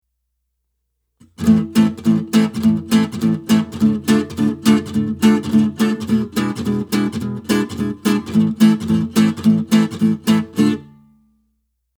Finally, coming back to the issue of upstrokes, one can apply pressure on the strings with the left hand before executing the upstroke, or one can totally (or almost) mute the strings, the difference is very subtle.